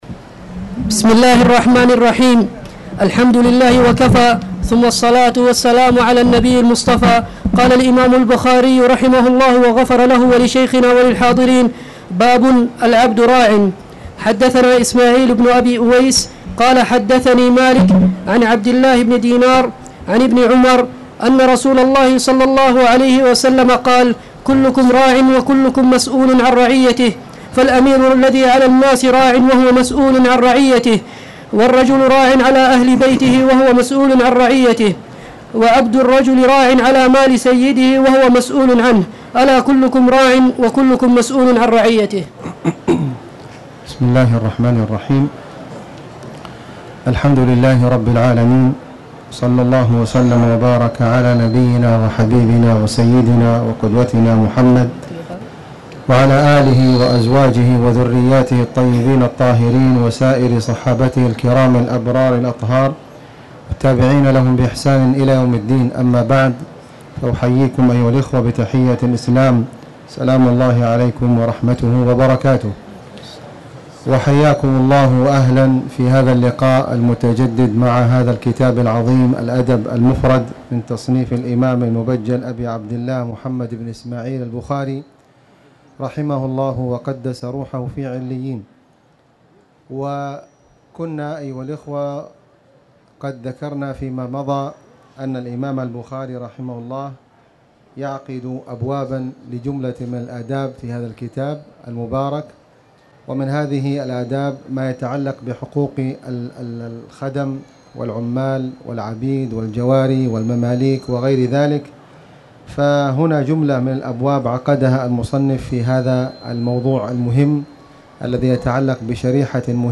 تاريخ النشر ١٢ ذو القعدة ١٤٣٧ هـ المكان: المسجد الحرام الشيخ: فضيلة الشيخ د. خالد بن علي الغامدي فضيلة الشيخ د. خالد بن علي الغامدي باب العبد راع